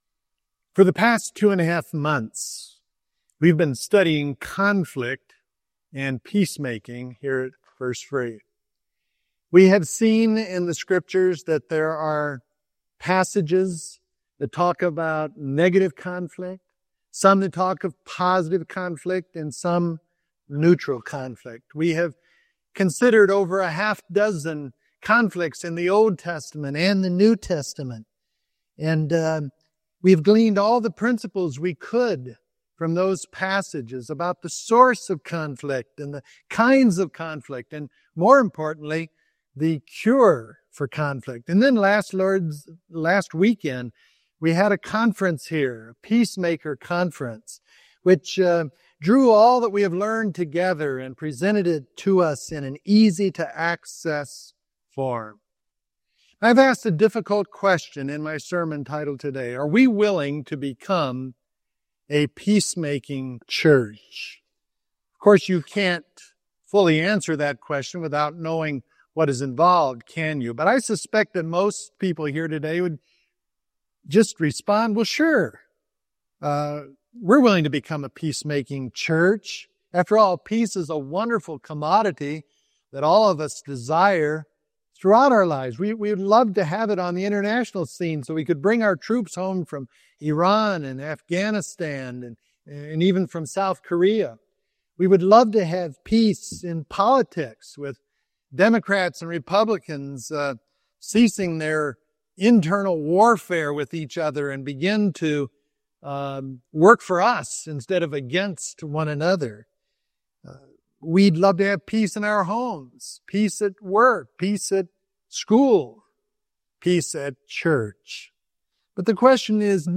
I’ve asked a difficult question in my sermon title this morning: Are We Willing to Become a Peacemaking Church ?